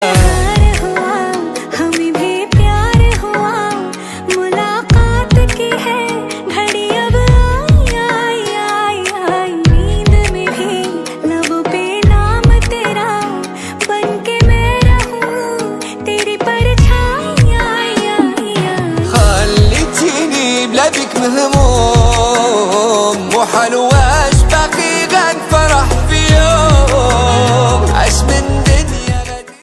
Female Version